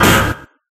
Buzzer3.ogg